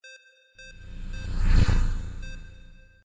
Spaceship passing by 06
Stereo sound effect - Wav.16 bit/44.1 KHz and Mp3 128 Kbps
previewSCIFI_SPACESHIP_PASSBY_WBHD06.mp3